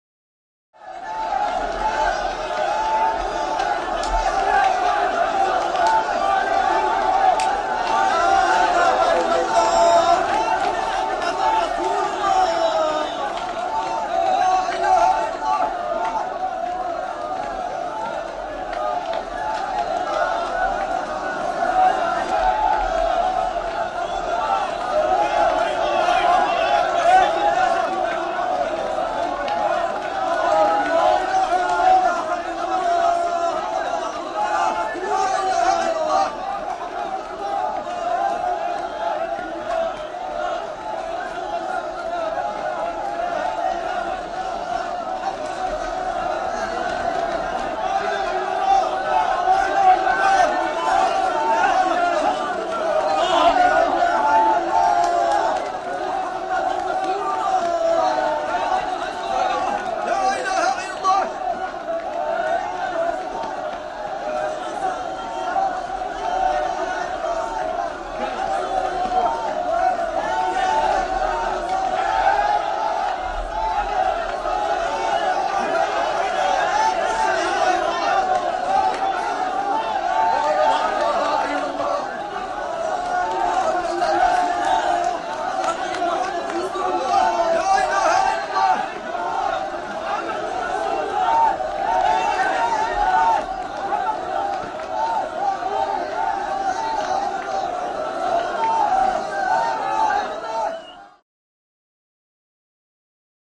Exterior Arabic Crowd Rioting.